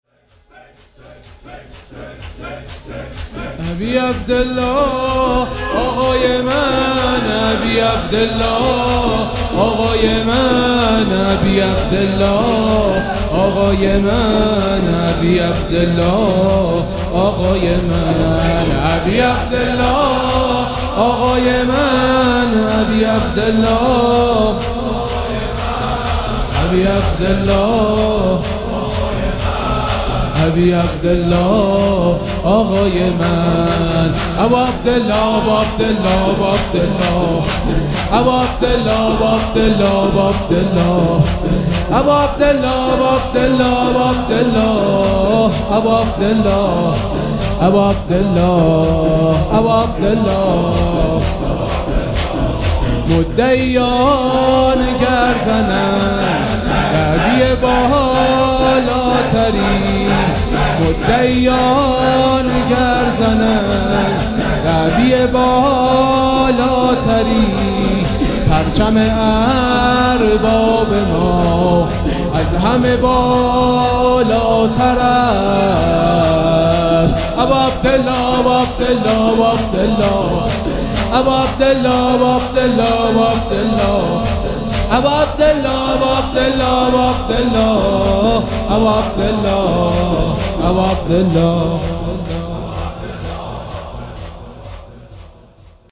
شور
مداح